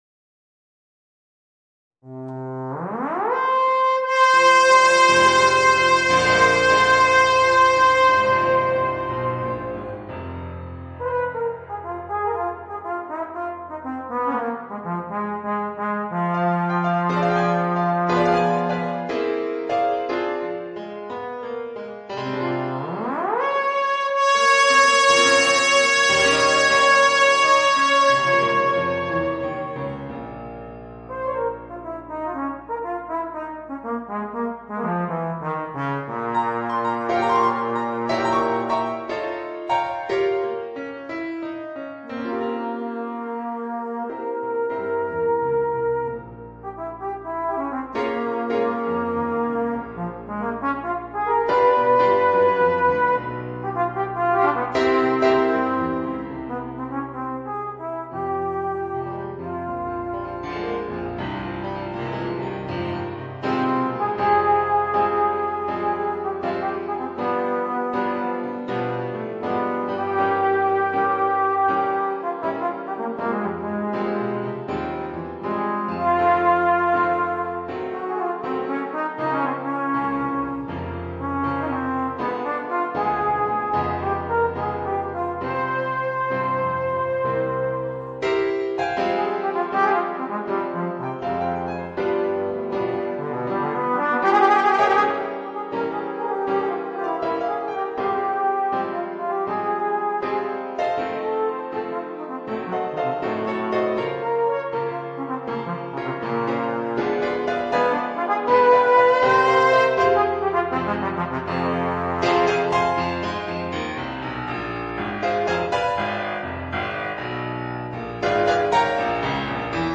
Voicing: Trombone and Piano or CD